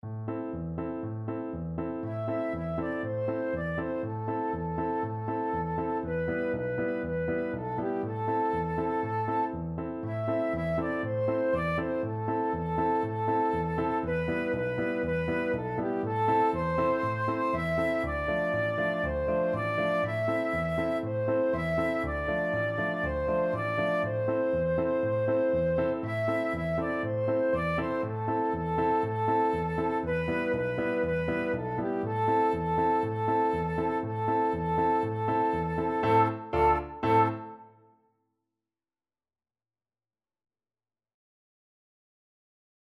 4/4 (View more 4/4 Music)
Allegro vivo (View more music marked Allegro)
G5-E6
World (View more World Flute Music)